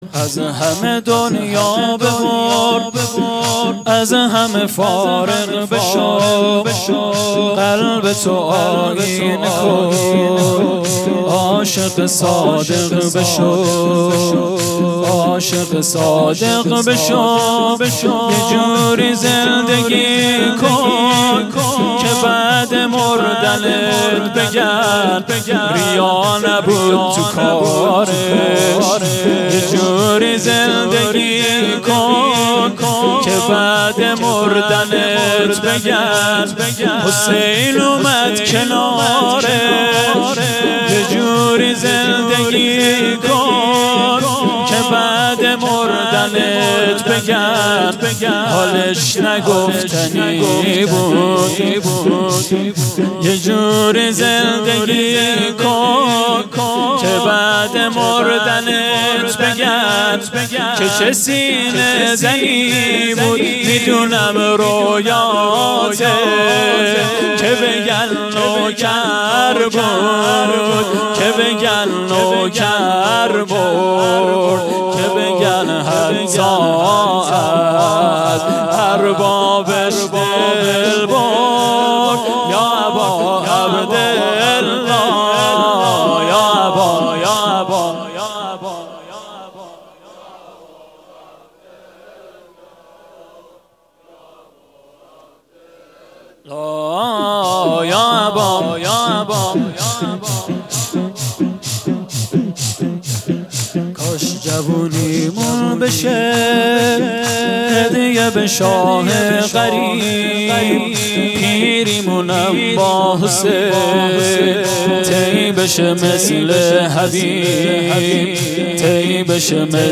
شور پایانی